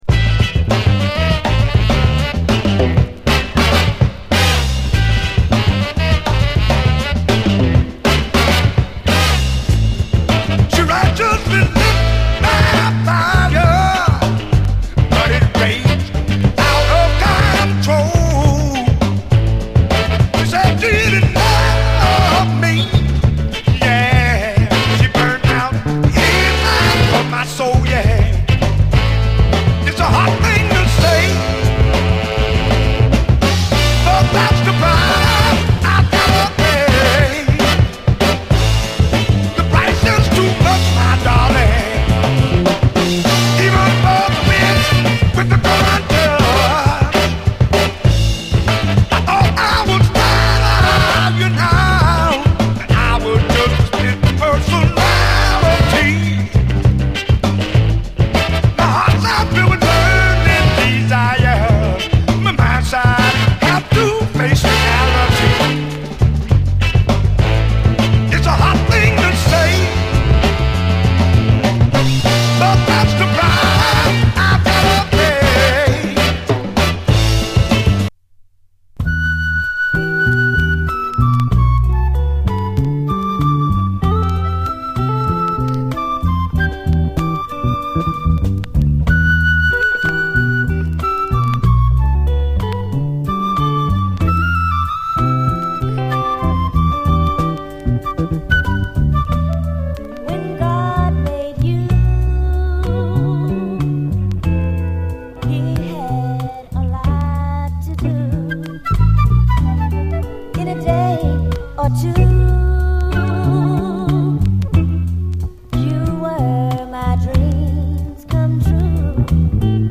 SOUL, 70's～ SOUL
ファットで豪快なブラス・ファンク満載の1ST！